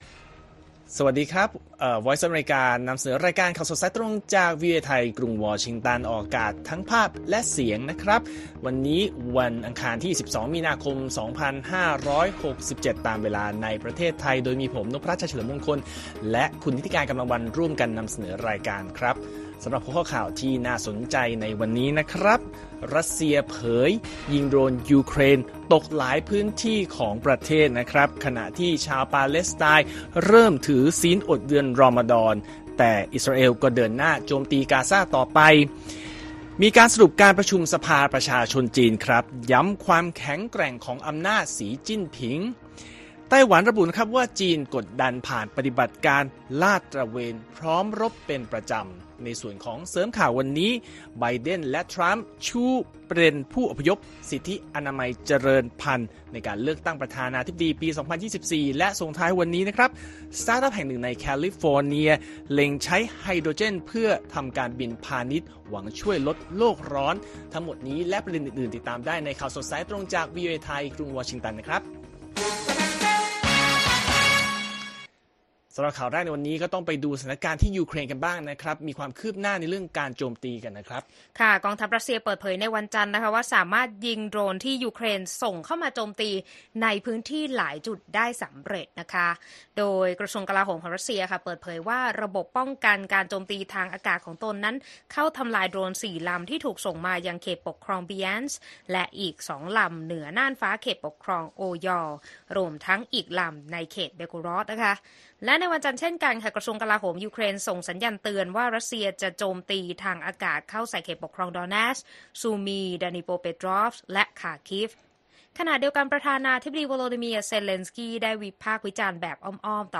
ข่าวสดสายตรงจากวีโอเอไทย 6:30 – 7:00 น. วันอังคารที่ 12 มีนาคม 2567